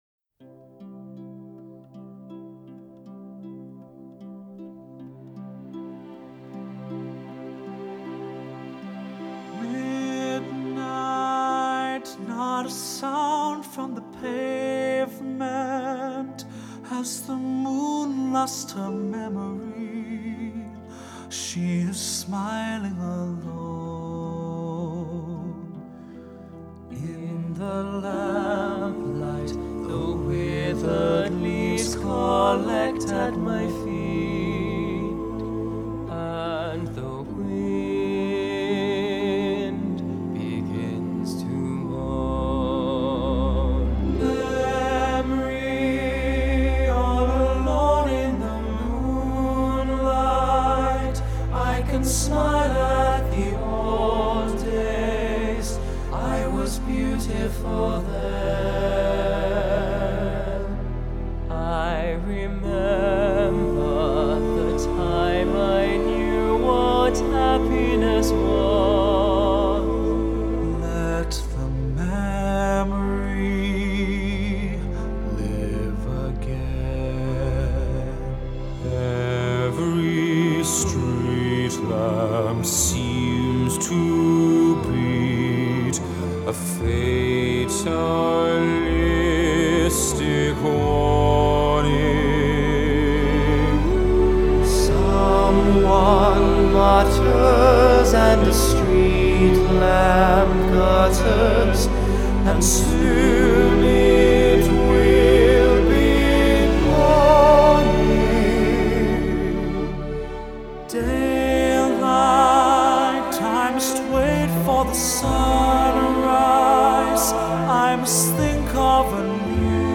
Genre: Vocal, Pop, Classical